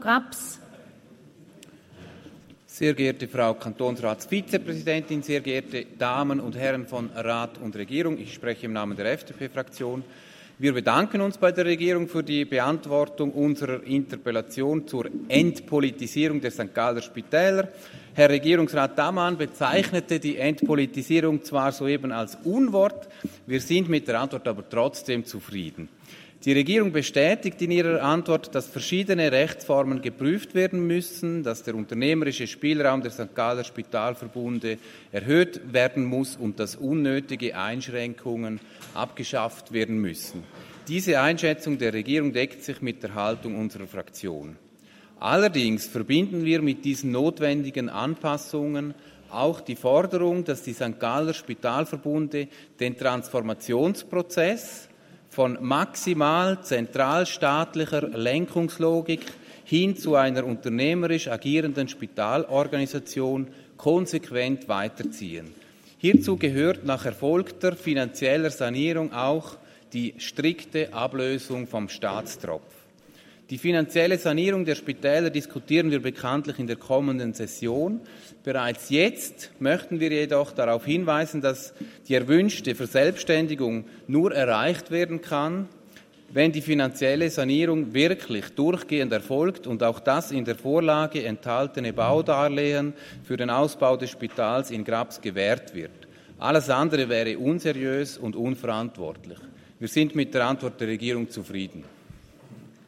20.9.2022Wortmeldung
Session des Kantonsrates vom 19. bis 21. September 2022